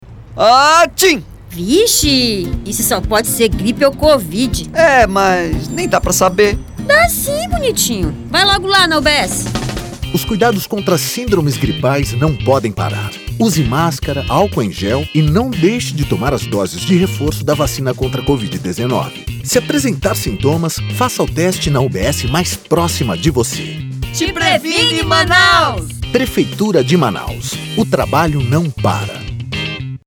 SPOT_Combate-as-Sindromes-Gripais-e-Covid.mp3